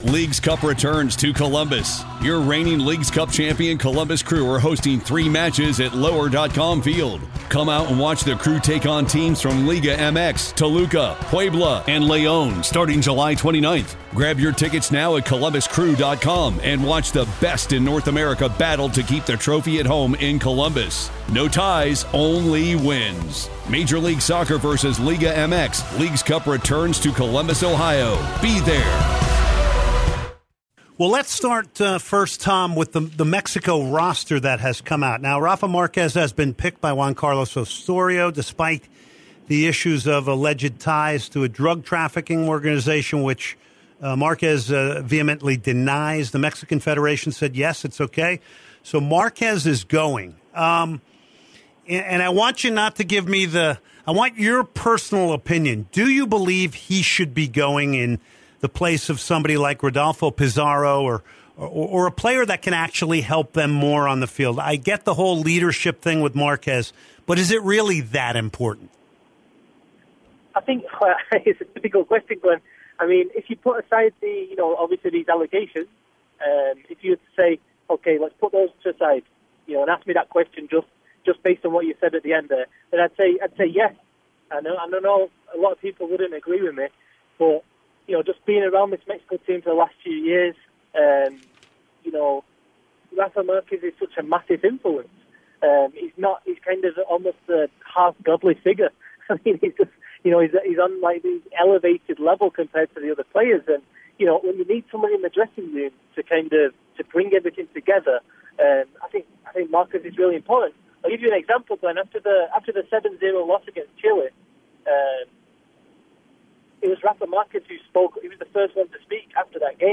ESPN FC Interview